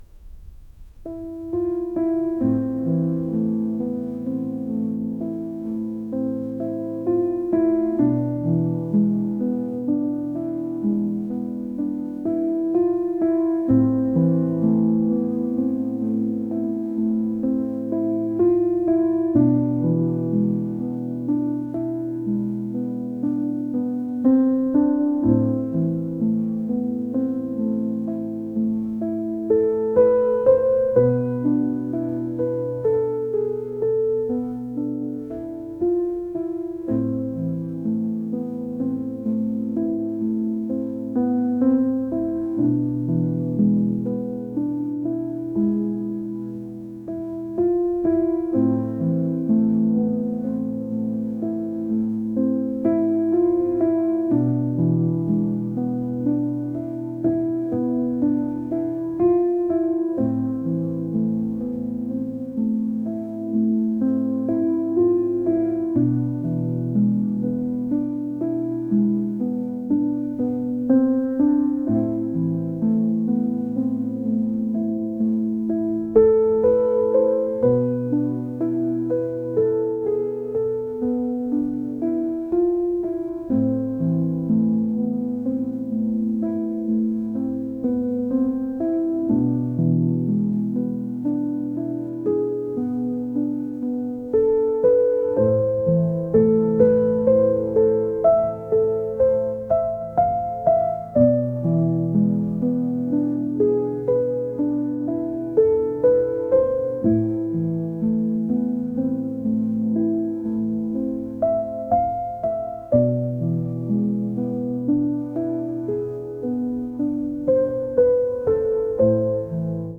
pop | ambient | cinematic